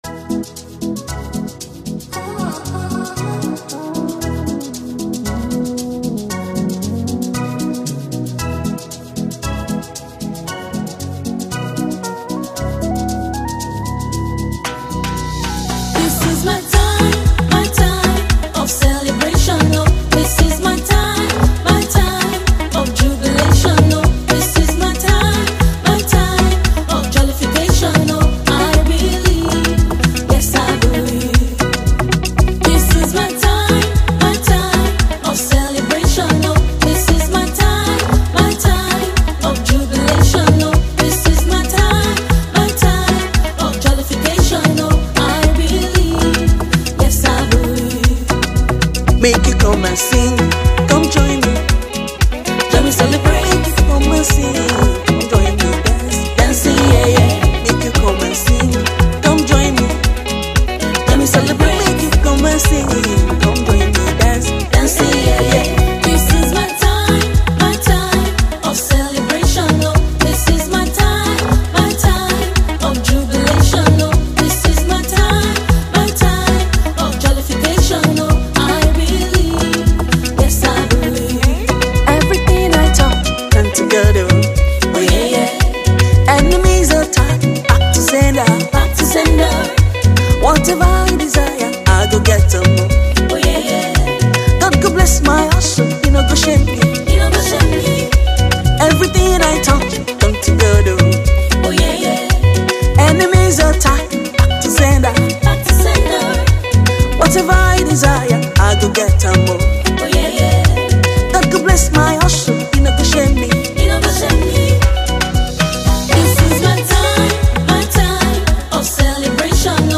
Nigerian talented gospel singer and songwriter